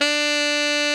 Index of /90_sSampleCDs/Giga Samples Collection/Sax/GR8 SAXES FF
TNR FFF-D4.wav